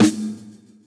Medicated Snare 4.wav